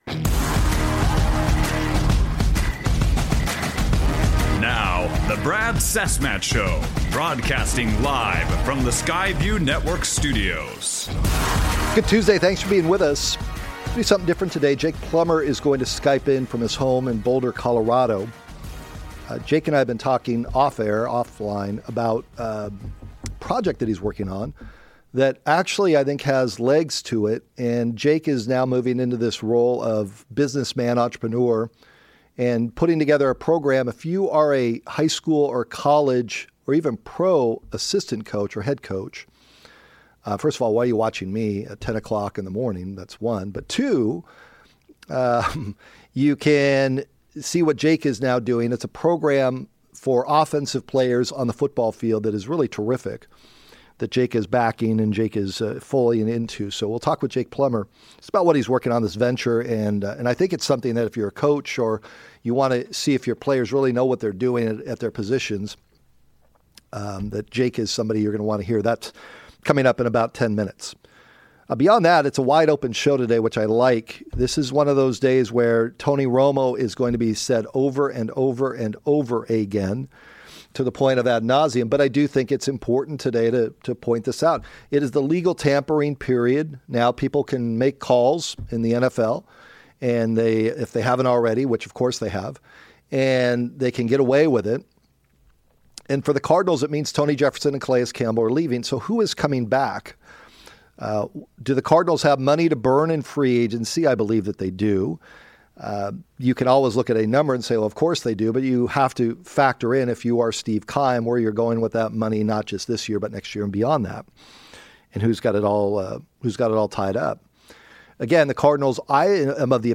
ASU legend, former Cardinals/NFL QB Jake Plummer (13:21 on podcast) Skyped in from Colorado to discuss his new project, Ready List Pro, which he's introducing to high schools and colleges on the west coast.